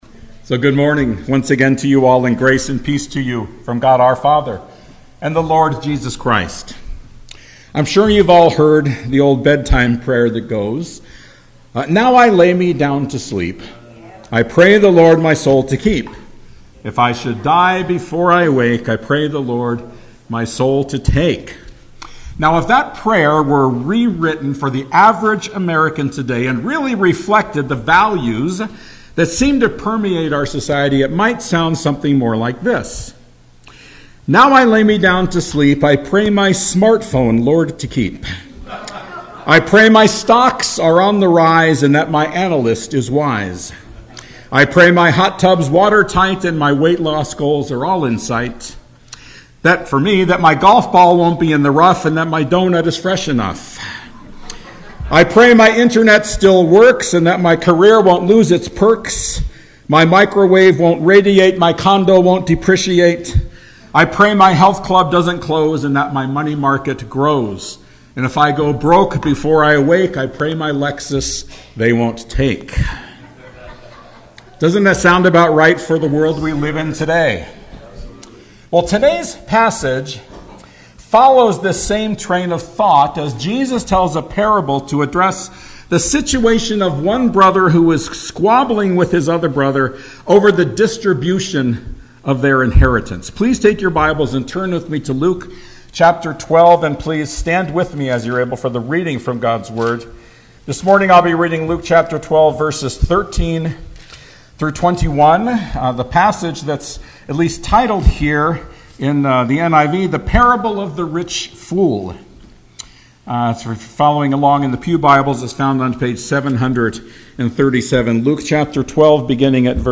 YLBC Sermons